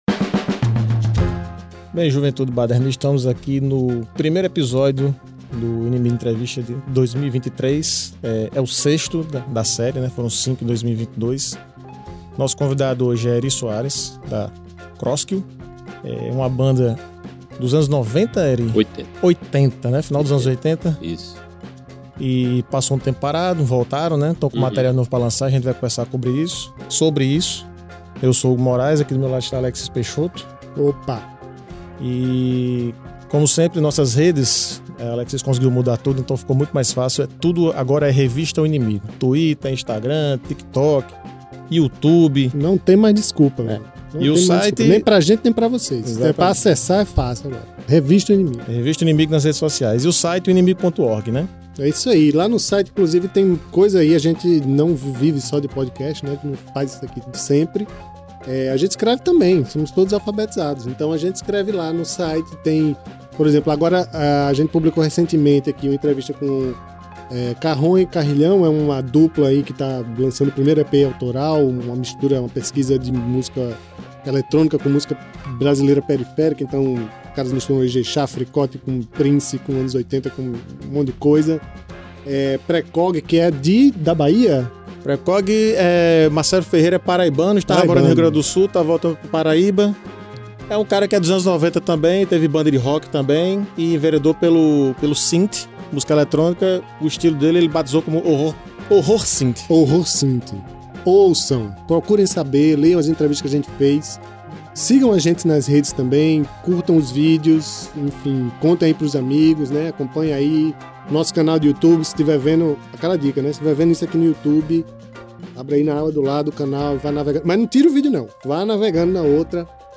Gravado e editado no Estúdio Fuga, Natal/RN.